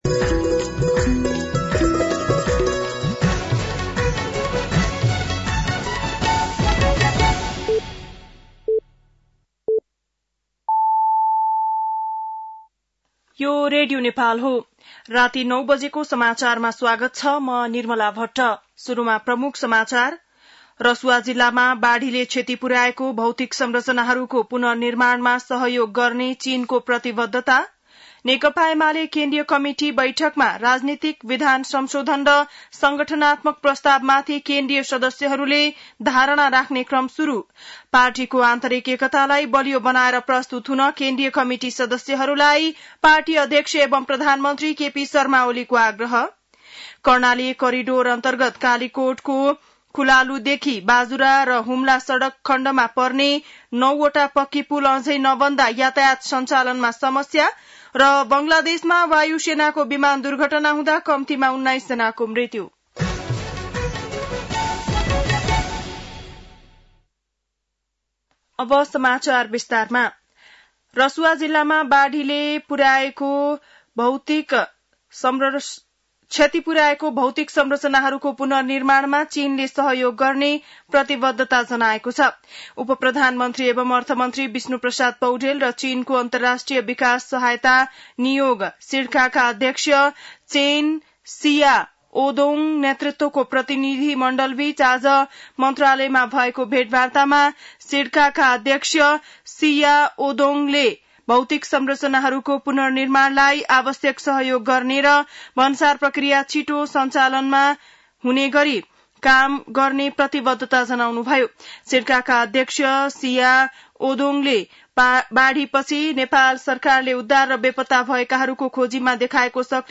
बेलुकी ९ बजेको नेपाली समाचार : ५ साउन , २०८२
9-PM-Nepali-NEWS-4-05.mp3